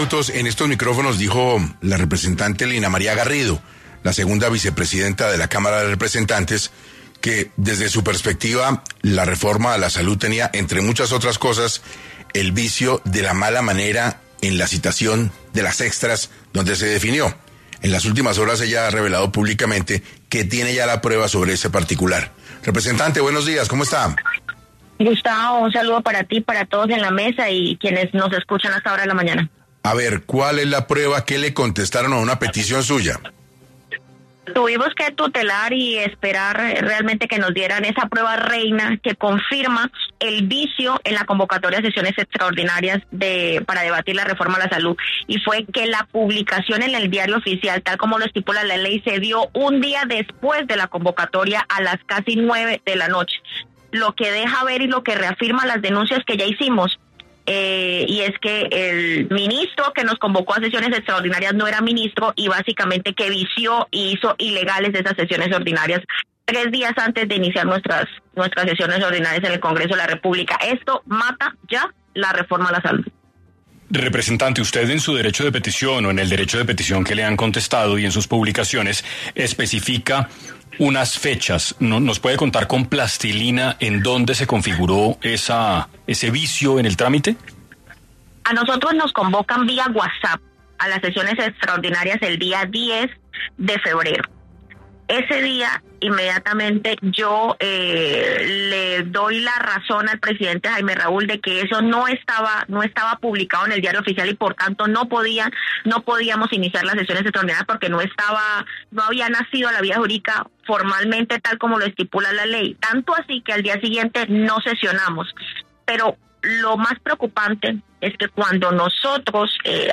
En 6AM de Caracol Radio estuvo Lina Garrido, representante a la Cámara por la Circunscripción de Arauca, quien habló sobre la sesión ordinaria que convocó el ministro de Interior Gustavo García, para debatir sobre la Reforma de la Salud